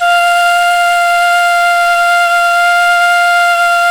NYE FLUTE04R.wav